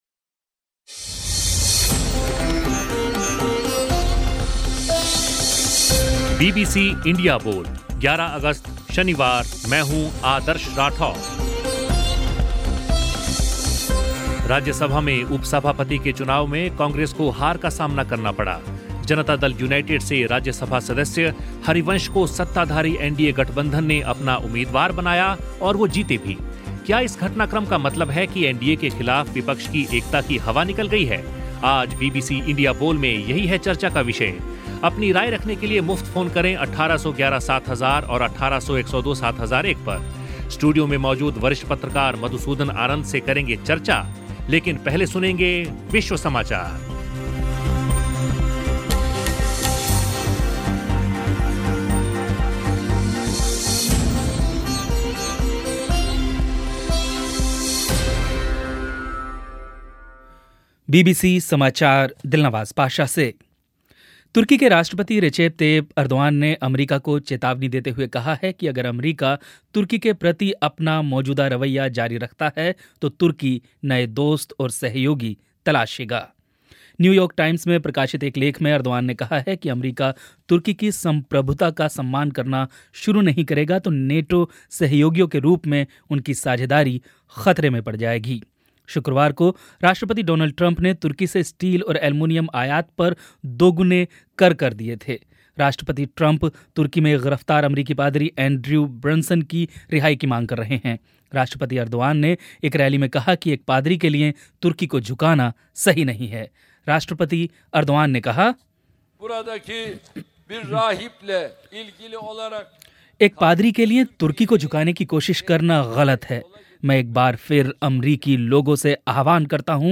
श्रोताओं के साथ इसी विषय पर चर्चा.